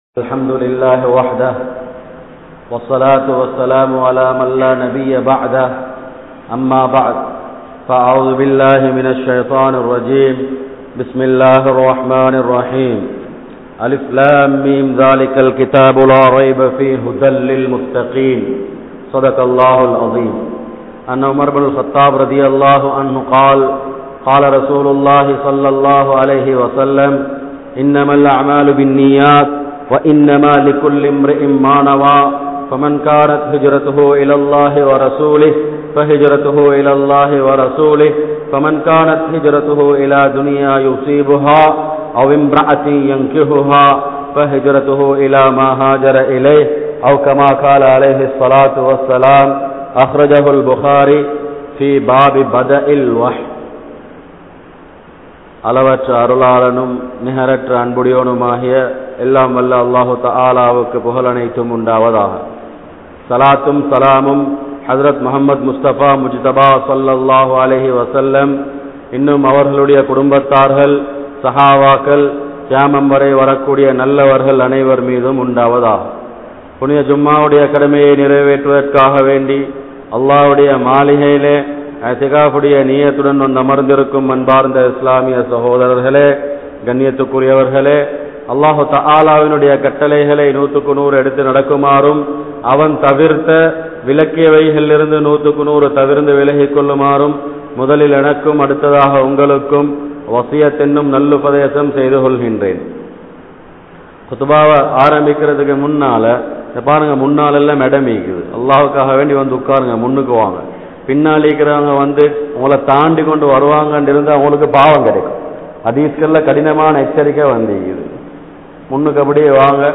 Anniyarhalin Thittangal | Audio Bayans | All Ceylon Muslim Youth Community | Addalaichenai
Gorakana Jumuah Masjith